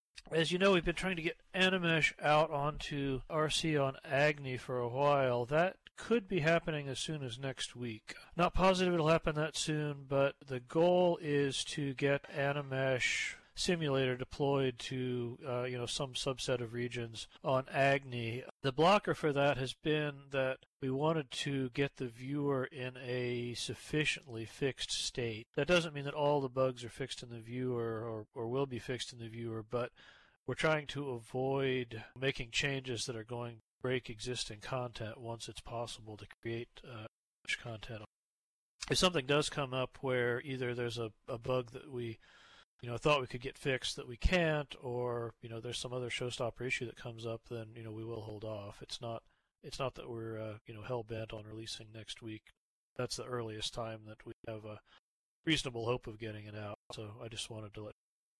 The following notes are taken from the Content Creation User Group (CCUG) meeting, held on  Thursday, June 14th, 2018 at 13:00 SLT.
Note that the audio presented here may not be in the exact order of discussion during the meeting; as subjects were at times returned to following their initial discussion, I have attempted to bring together key points of discussion by topic / subject matter.